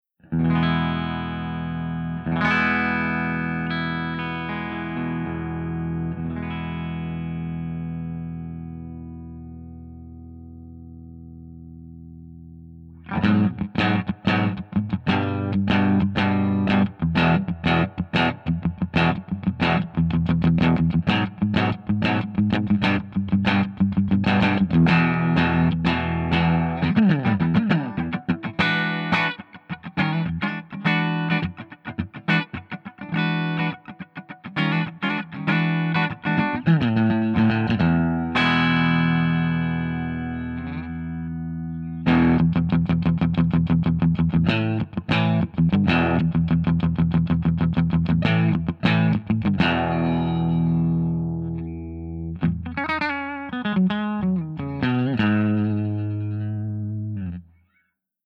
068_FENDERSUPERREVERB_BRIGHTREVERB_SC.mp3